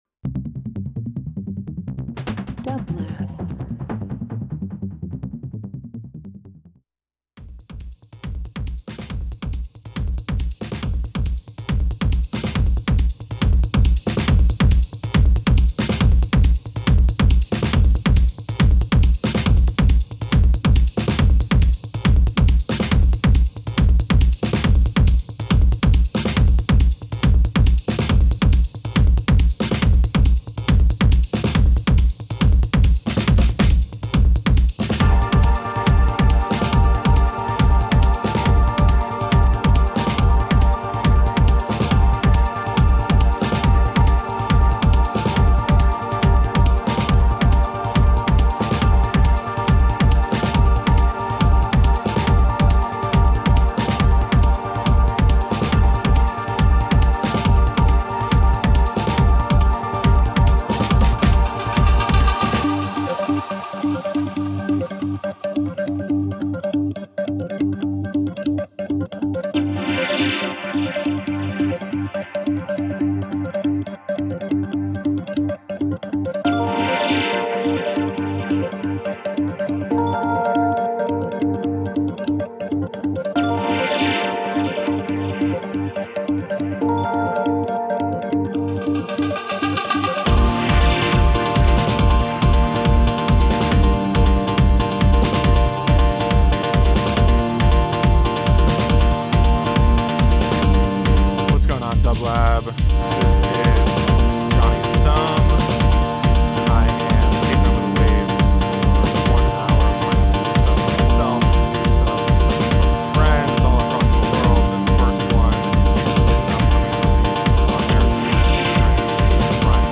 Dance Electronic House Techno